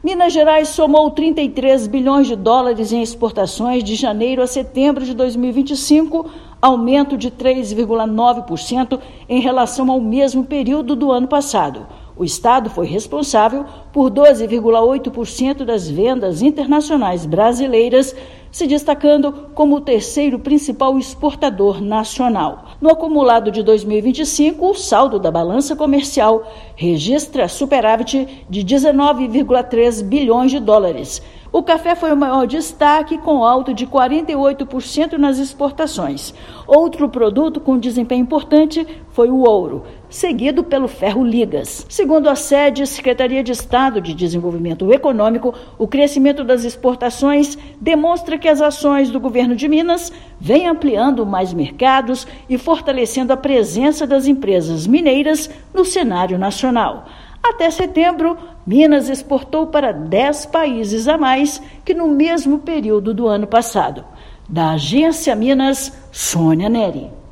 Com incremento de comercialização de produtos e novos parceiros, estado teve o maior superávit no Brasil em setembro. Ouça matéria de rádio.